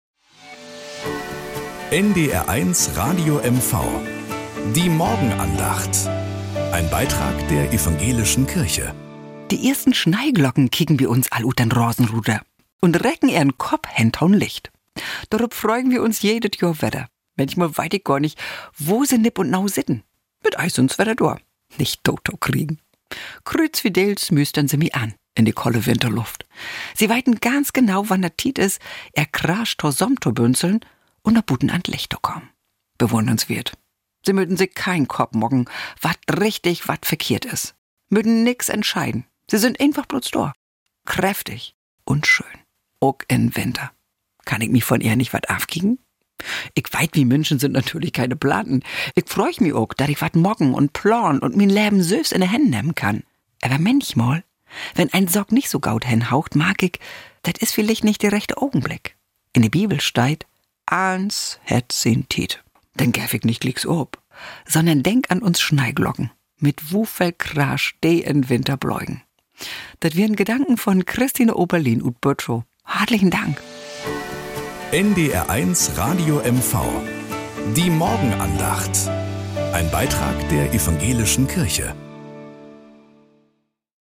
Morgenandacht auf NDR 1 Radio MV - 24.02.2025